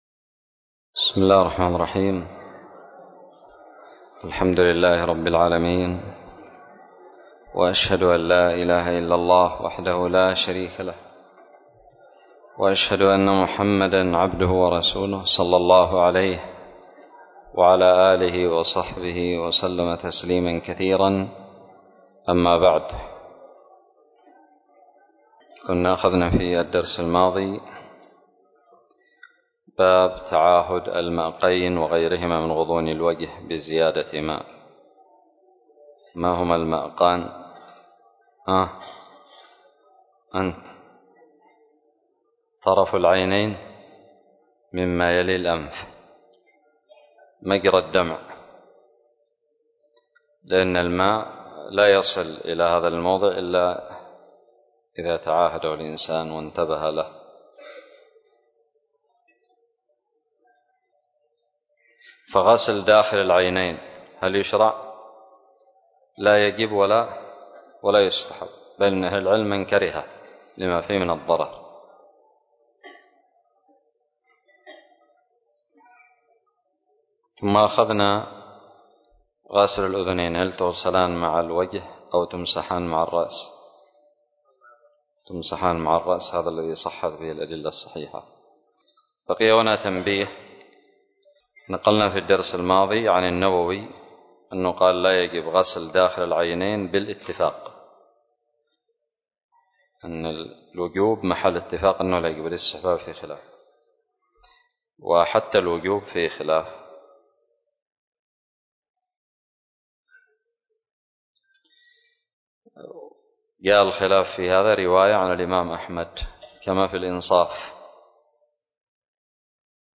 الدرس الخامس عشر بعد المائة من كتاب الطهارة من كتاب المنتقى للمجد ابن تيمية
ألقيت بدار الحديث السلفية للعلوم الشرعية بالضالع